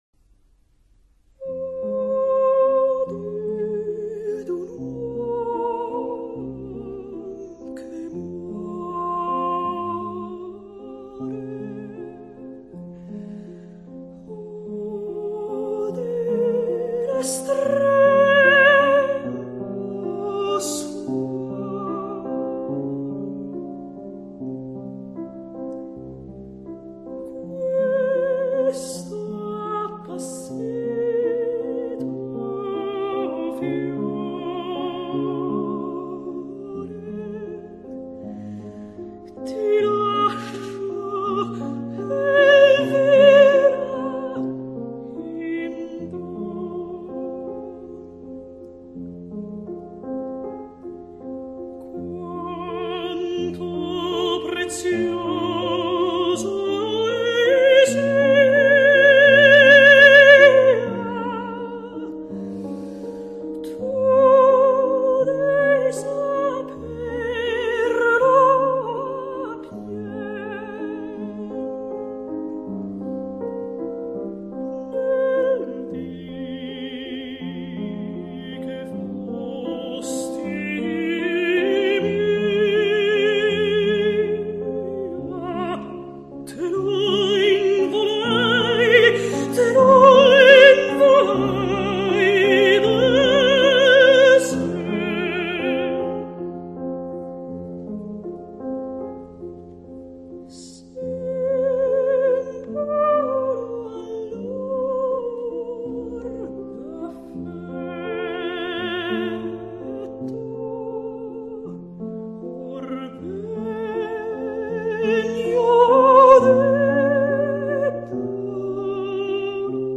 Mezzo